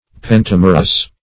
Pentamerous \Pen*tam"er*ous\, a. [Penta- + Gr. ? part.]